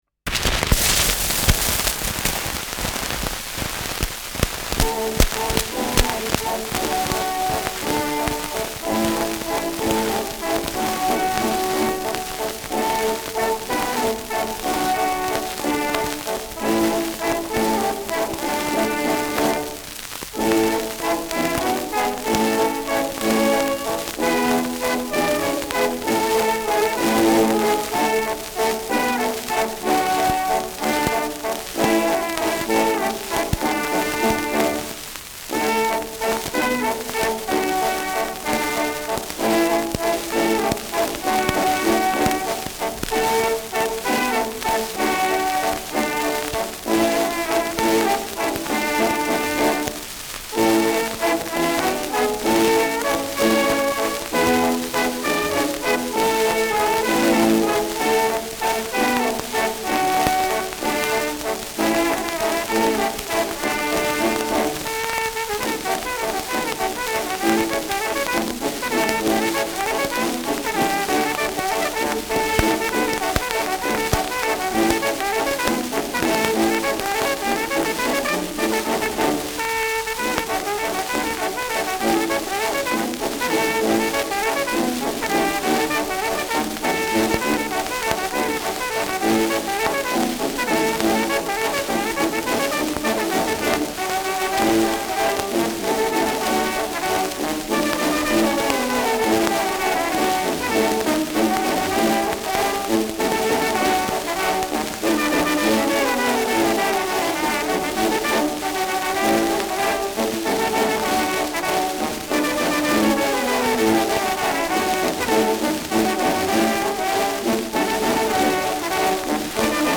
Schellackplatte
[Stuttgart] (Aufnahmeort)